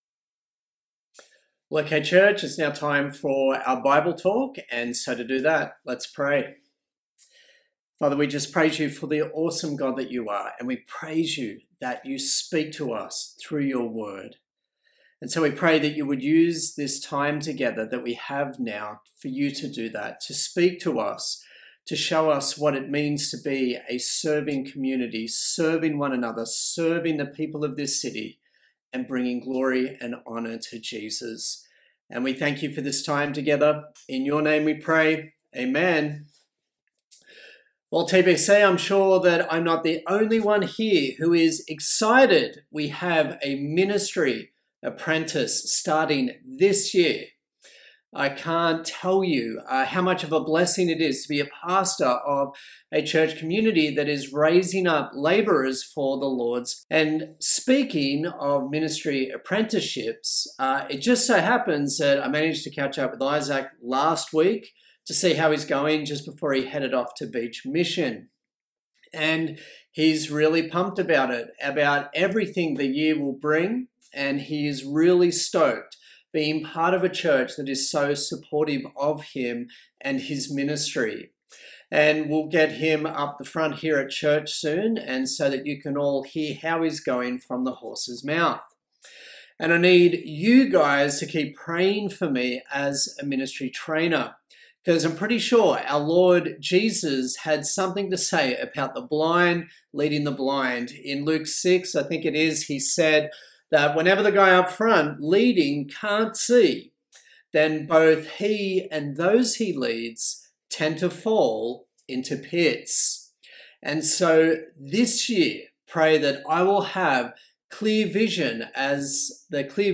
Acts Passage: Acts 18:1-11 Service Type: Sunday Service